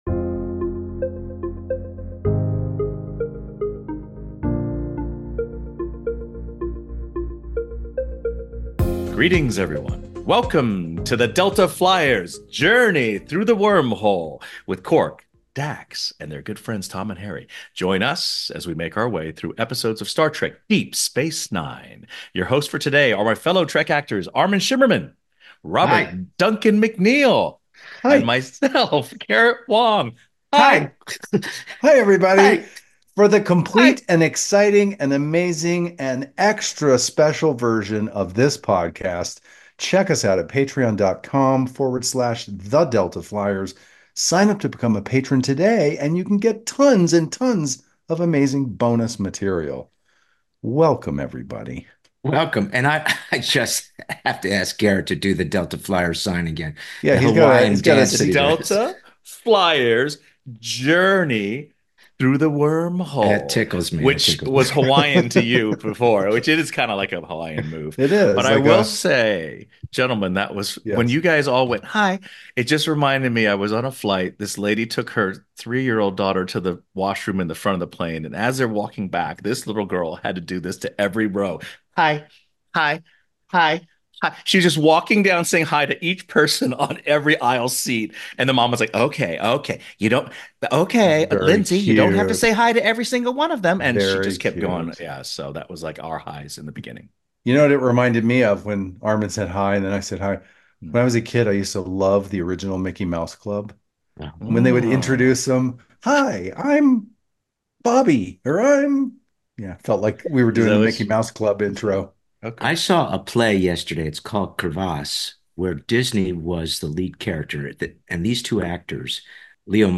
In each podcast release, they will recap and discuss an episode of Star Trek: Deep Space Nine. This week’s episode, The House of Quark, is hosted by Garrett Wang, Robert Duncan McNeill, & Armin Shimerman.